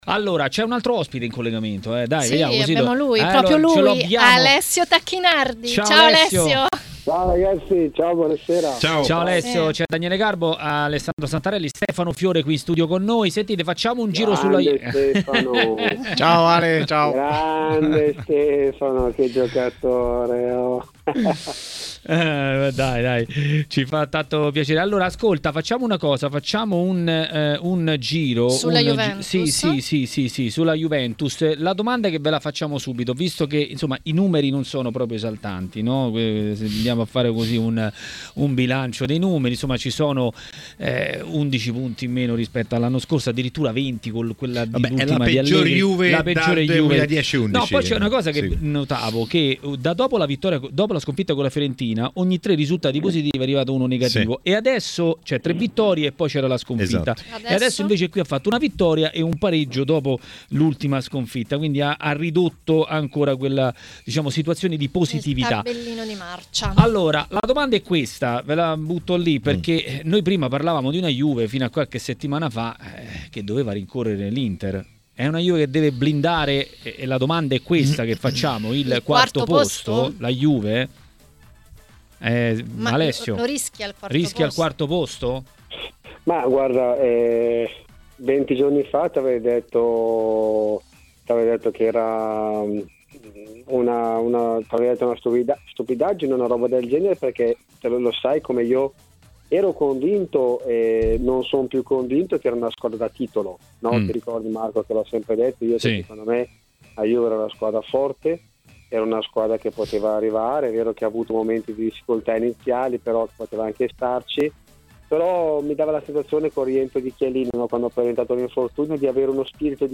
TMW Radio Regia Ascolta l'audio Alessio Tacchinardi e Daniele Garbo ospiti nel Maracanà Show.